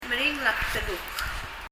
ｂが子音の前にあるときは、[p]音になります。 [pðɛluk] と発音されているはず。